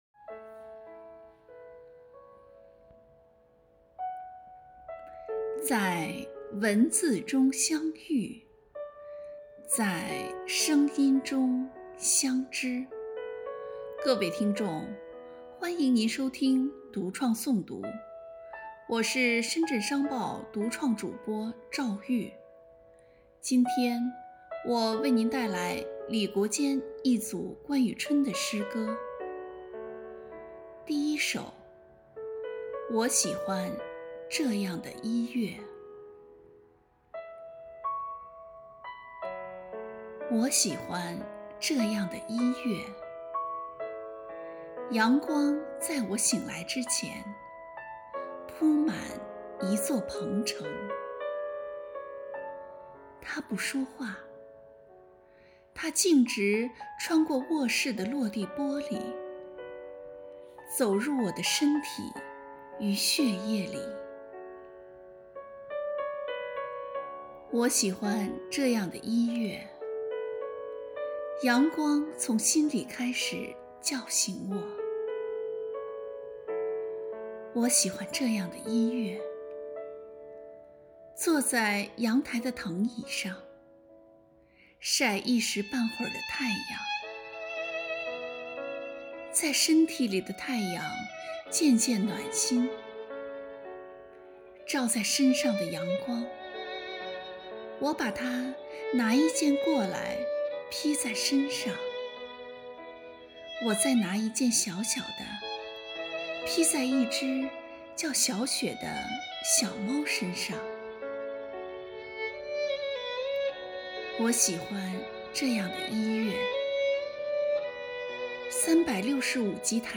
深圳商报·读创客户端“读创诵读”